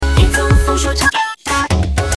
彝族 风说唱